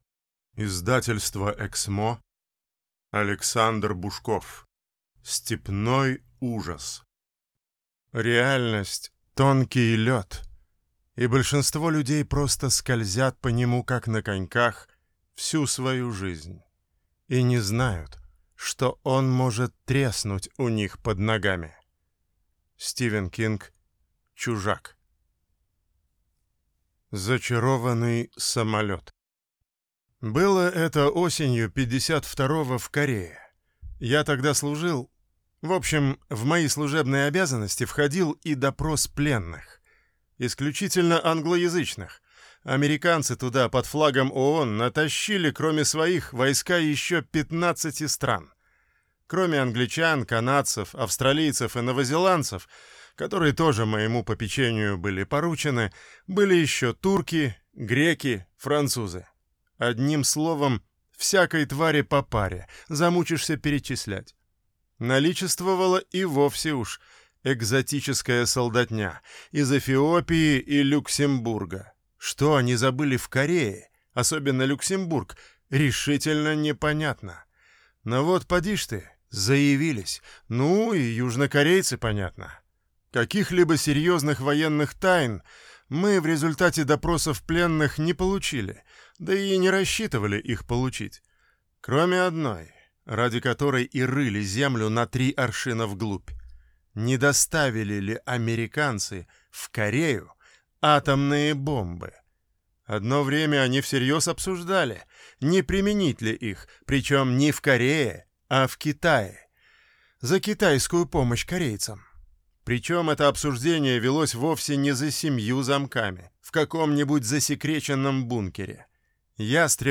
Аудиокнига Степной ужас | Библиотека аудиокниг